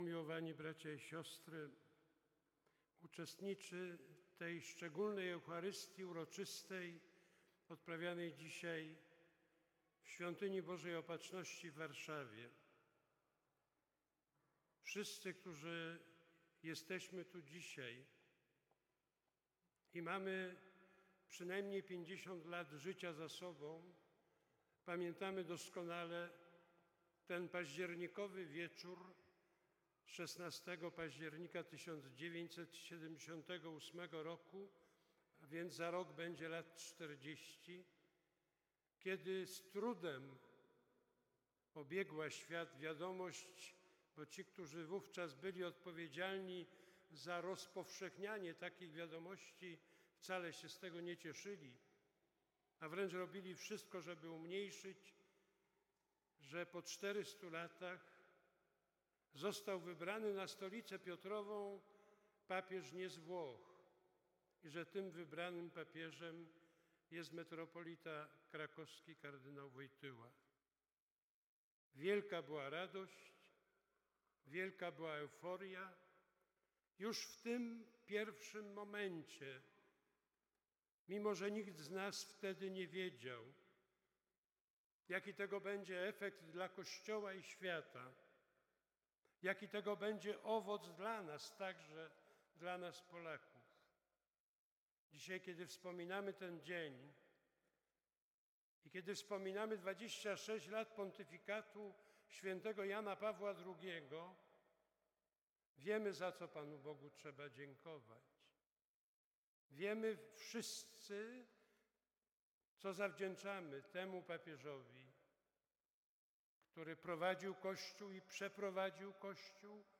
Kulminacyjne wydarzenia XVII Dnia Papieskiego miały miejsce w Świątyni Opatrzności Bożej, którą wypełnili Darczyńcy oraz osoby związane z Fundacją Dzieło Nowego Tysiąclecia.
O godzinie 12.00 odprawiona została uroczysta Msza Święta.
Ksiądz kardynał, który jest przewodniczącym Rady Fundacji Dzieło Nowego Tysiąclecia, wygłosił również homilię. Wspominał październikowy wieczór sprzed 39 lat, gdy świat obiegła wiadomość o wyborze krakowskiego kardynała na urząd następcy świętego Piotra. Przypomniał homilie Świętego Jana Pawła II dotyczące młodych a w kontekście czytań liturgicznych o winnicy podkreślał jak ważnym dziełem jest troska o młodych, o ich kształcenie i formację w duchu Ewangelii.
Kardynal-Kazimierz-Nycz-8-pazdziernika-2017-Dzien-Papieski.mp3